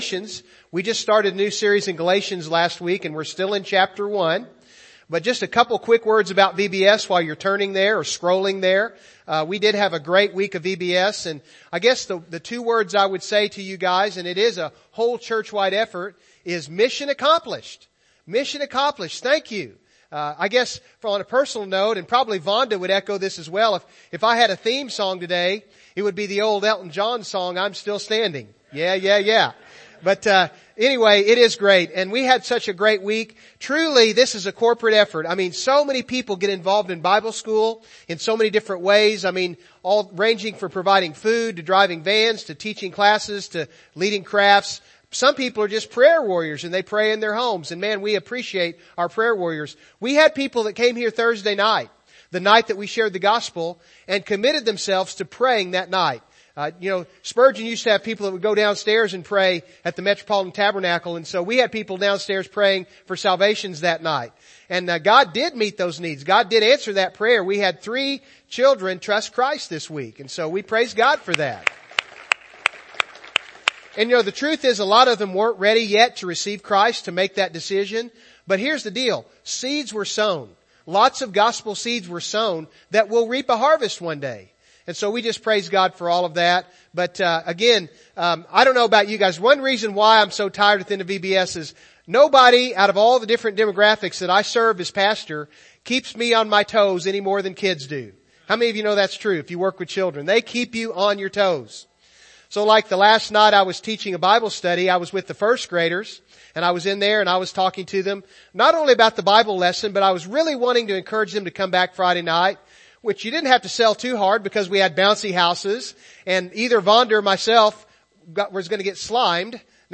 Service Type: Morning Service